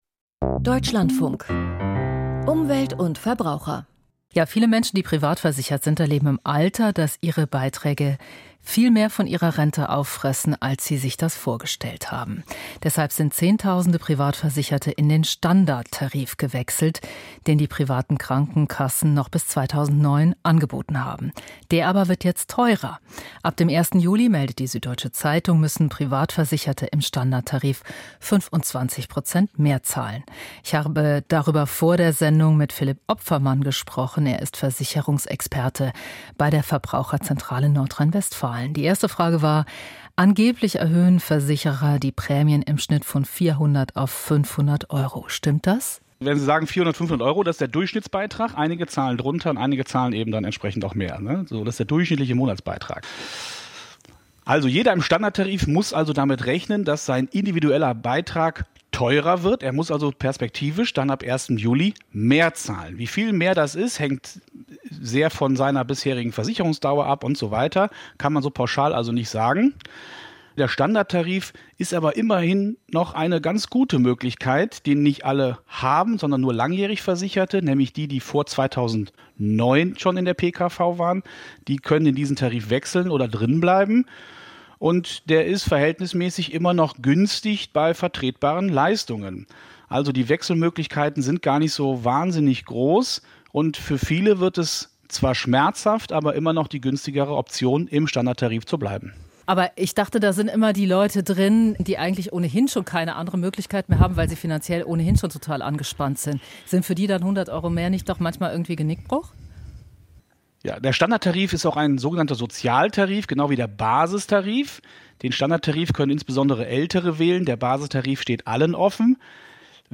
Beitragsschock für Privatversicherte: Interview